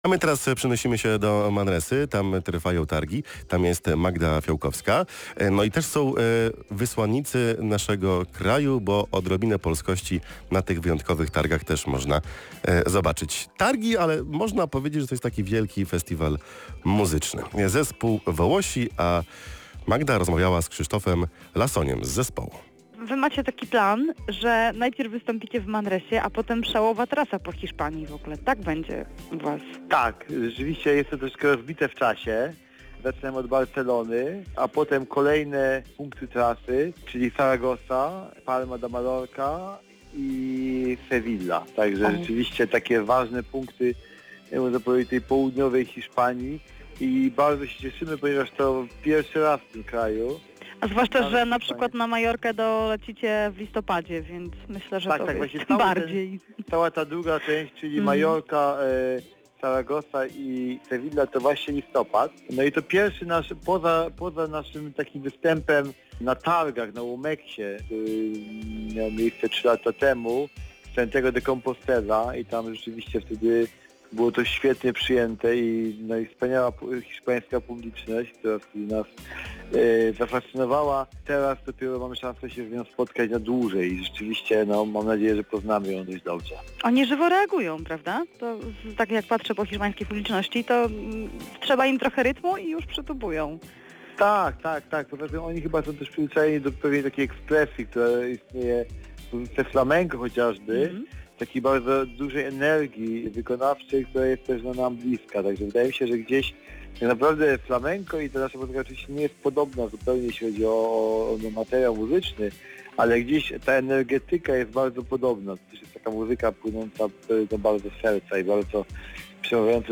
Entrevista a Volosi al programa Przedpoludnik